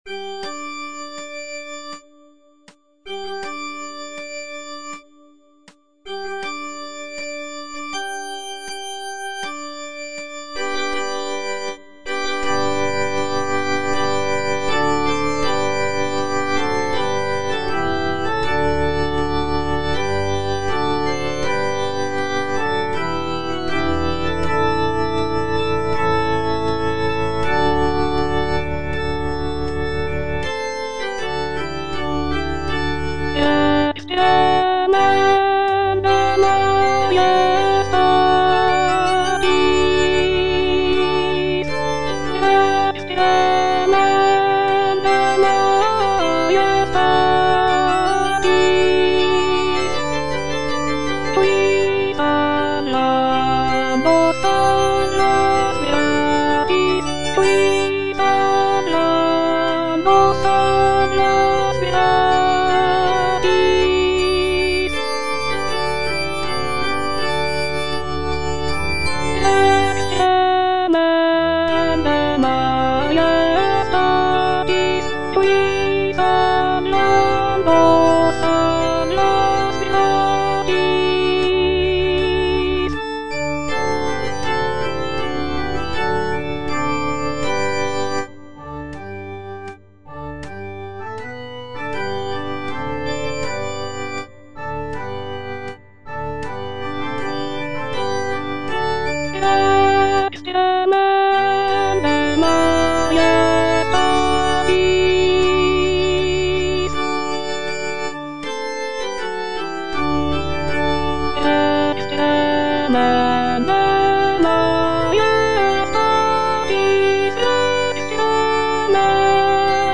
(alto I) (Voice with metronome) Ads stop
is a sacred choral work rooted in his Christian faith.